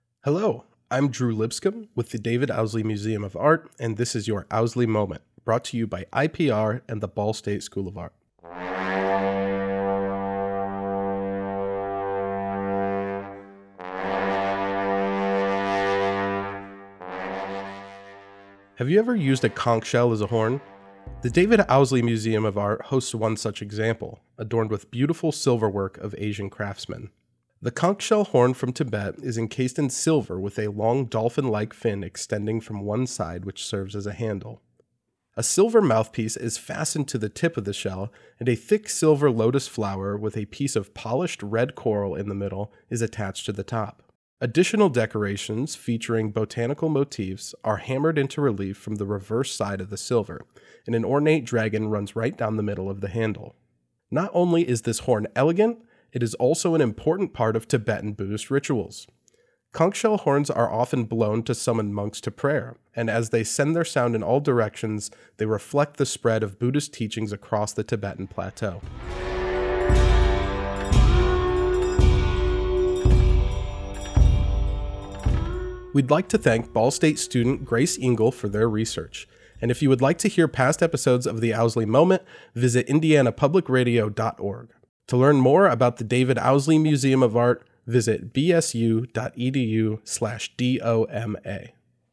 Music used in this episode:
They are produced in collaboration with the David Owsley Museum of Art and Indiana Public Radio and are voiced by Ball State students.